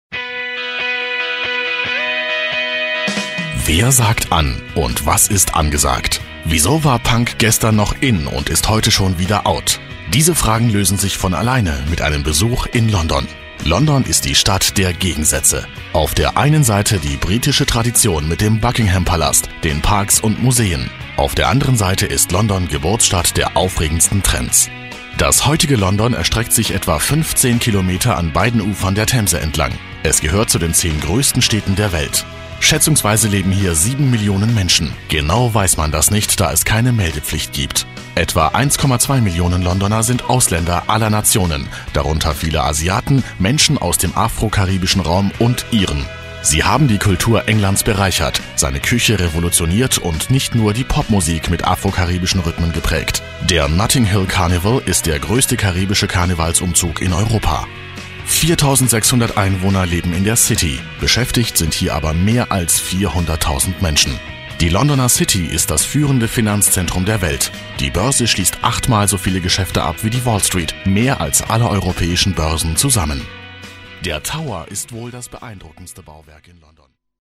Synchronsprecher, Werbesprecher. Tiefe, maskuline Stimme. Sehr variabel, von seriös über freundlich bis angsteinflößend
Sprechprobe: Werbung (Muttersprache):